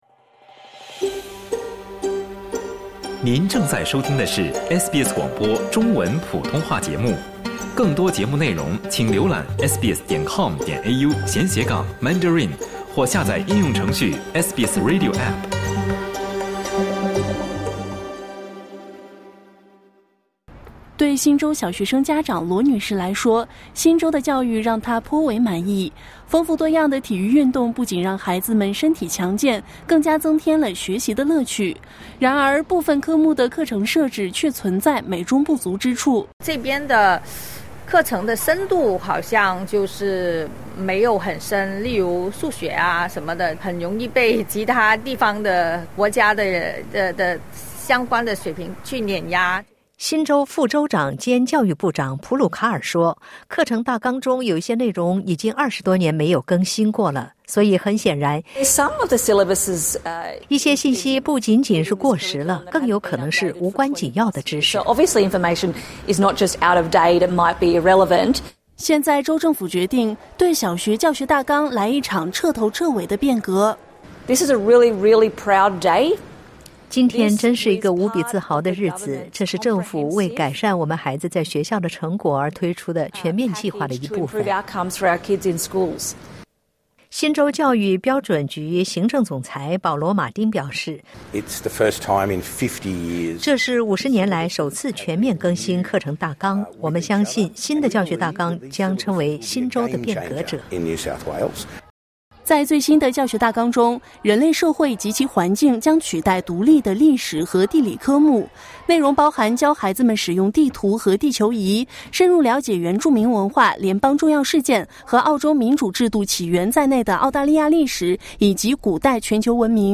修改的教学大纲包括了必修的公民与公民身份教学内容，涵盖了民主投票的作用和历史。点击音频收听详细报道